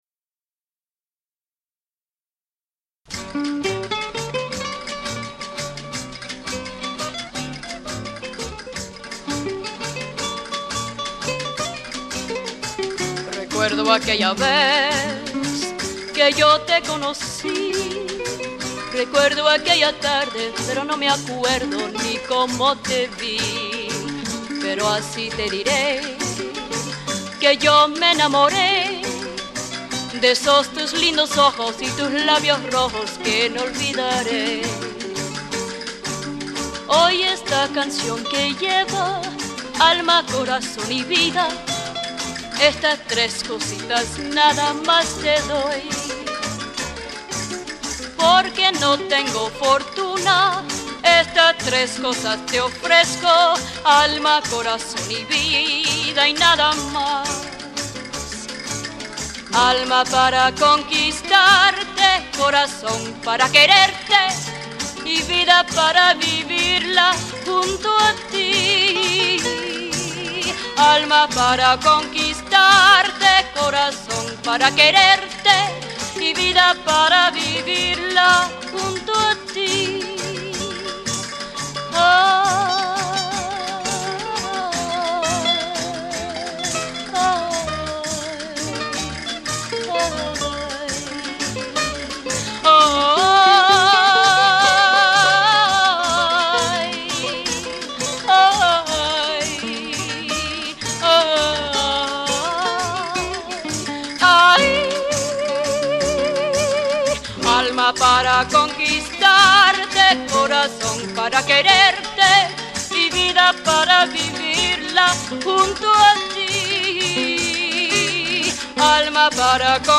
as well as her naturally fresh vocal quality.